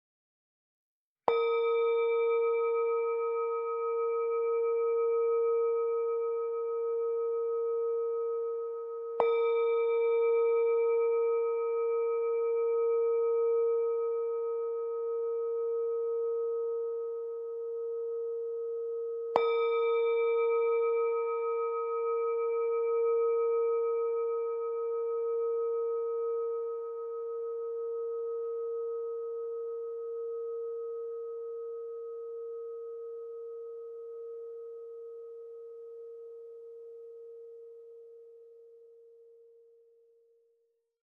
When softly tapped, Sonic Energy Origin Series singing bowls release a fascinating, multi-layered, and colorful sound that resonates deeply within the soul. Over a rich fundamental tone, entire waterfalls of singing overtones emerge to float freely in space and unfurl inside the body. Once the sound starts vibrating, it won't stop; even a minute later, a soft reverberation can still be felt.